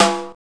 HI RINGER.wav